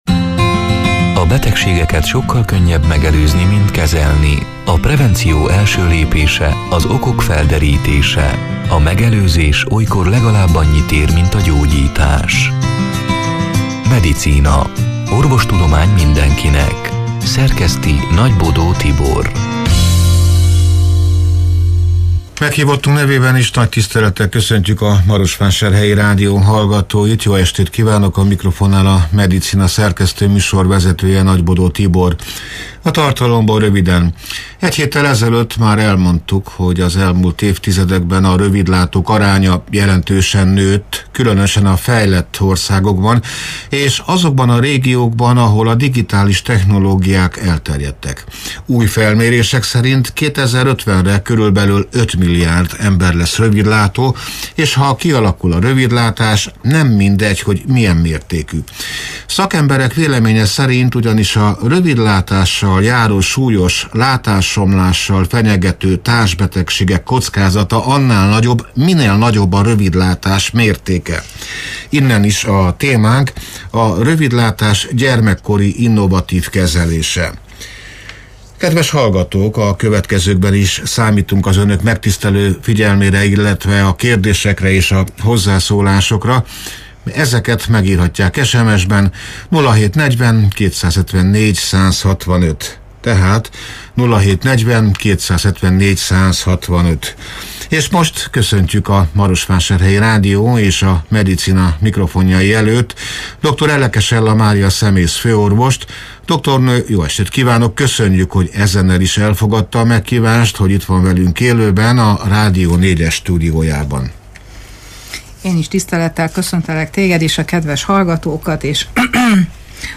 (elhangzott: 2025. augusztus 27-én, szerdán este nyolc órától élőben)